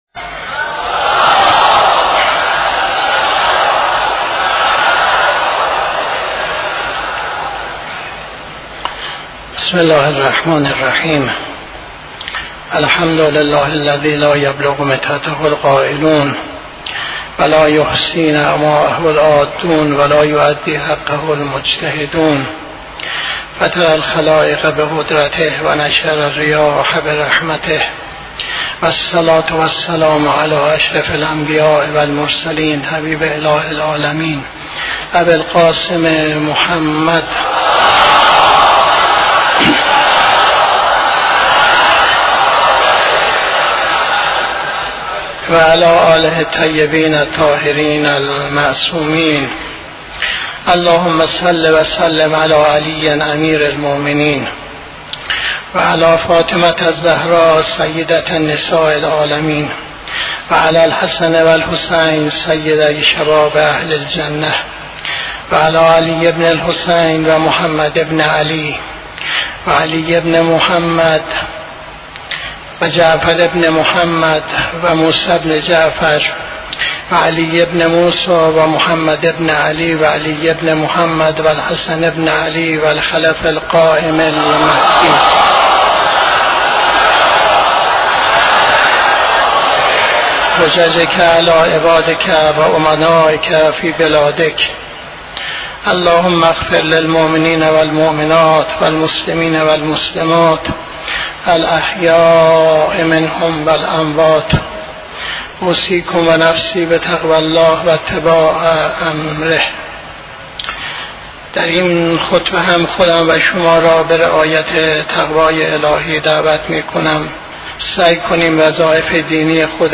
خطبه دوم نماز جمعه 21-06-82